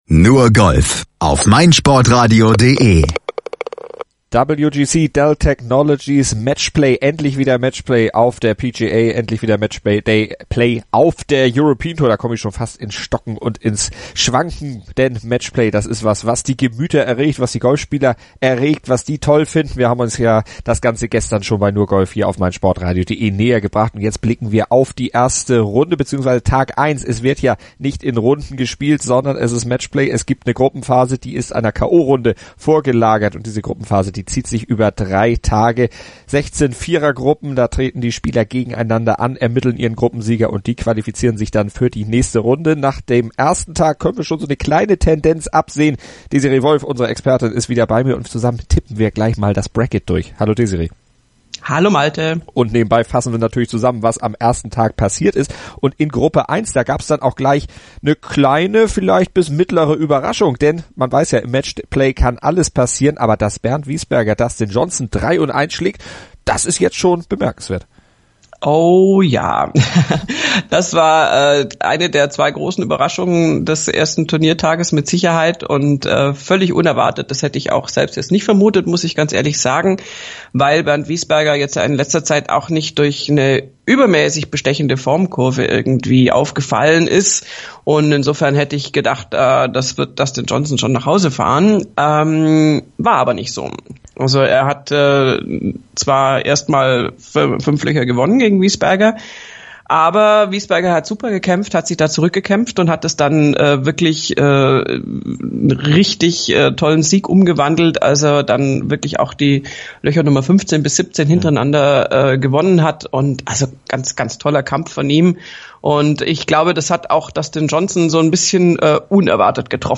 Wie er seinen Sieg einachätzt, hört ihr im Podcast im Interview. Und Ian Poulter zeigte wieder einmal, warum er als einer der großen Namen beim Duell Mann gegen Mann gehandelt wird.